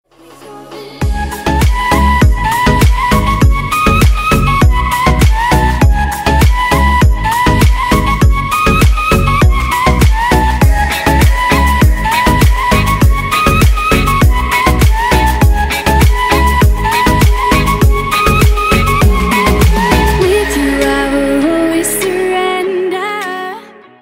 • Качество: 320, Stereo
поп
dance
восточные